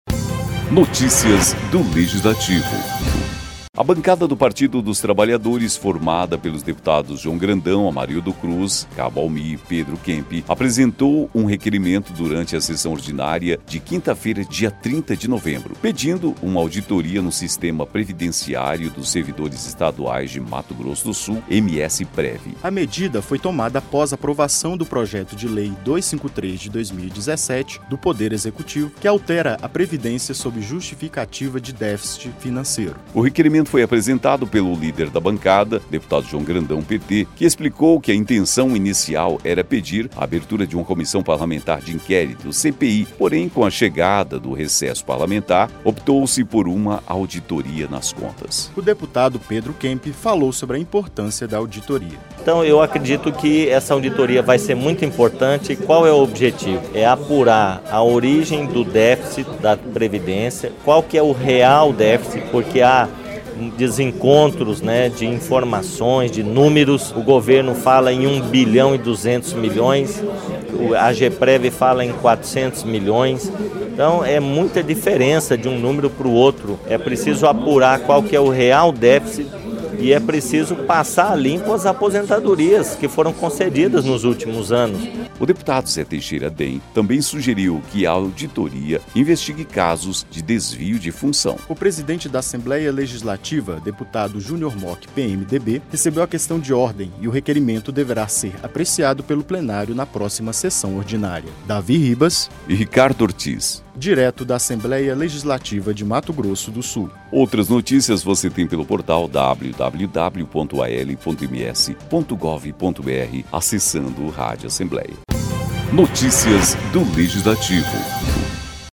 O deputado Pedro Kemp discursou na tribuna a importância de aprovar a abertura de uma auditoria.